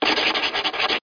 1 channel
pencil04.mp3